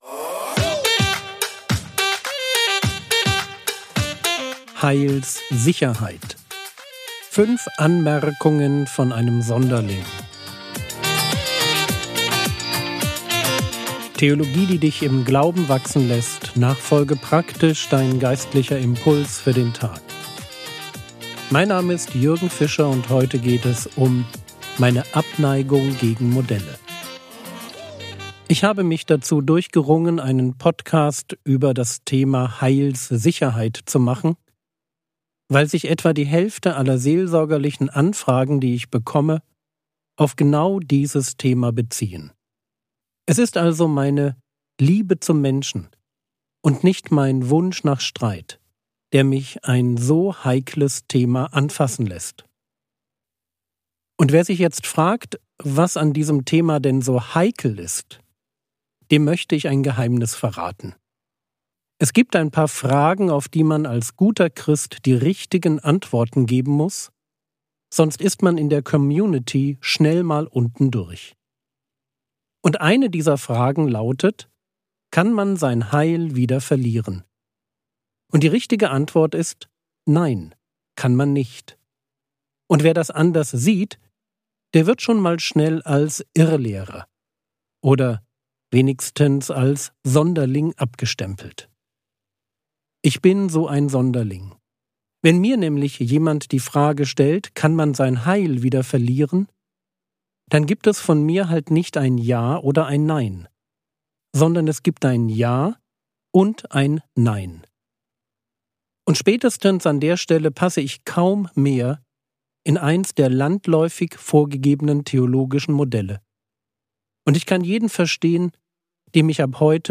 Heilssicherheit (1/5) ~ Frogwords Mini-Predigt Podcast